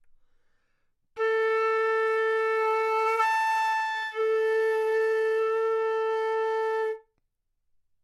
长笛单音（吹得不好） " 长笛 A4 不好的动态
描述：在巴塞罗那Universitat Pompeu Fabra音乐技术集团的goodsounds.org项目的背景下录制。
Tag: 好声音 单注 多样本 A4 纽曼-U87 长笛